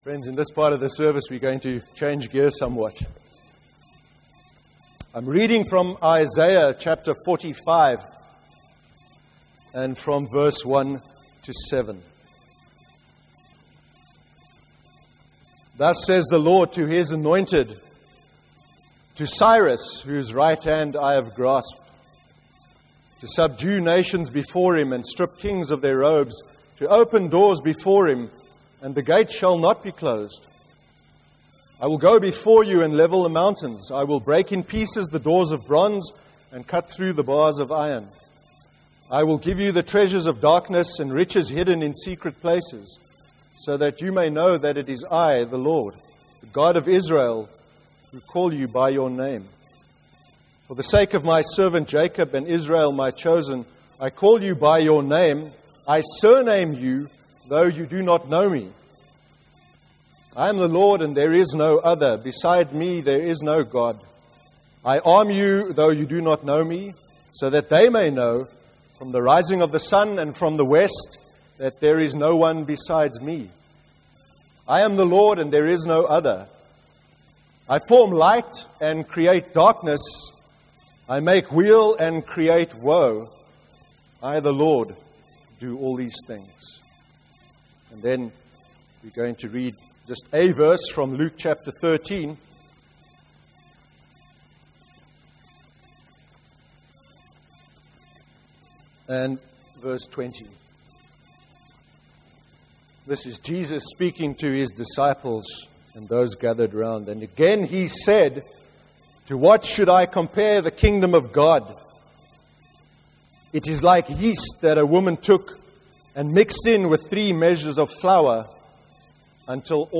25/08/13 sermon – Evil in the name of Religion (Isaiah 45:1-7, and Luke 13:20-21)